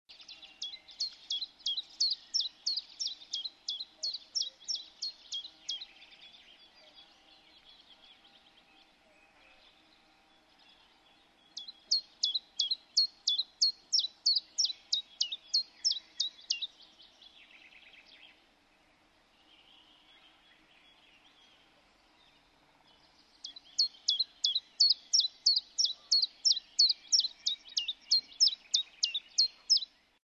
Tjiftjaf
Tjiftjaf.mp3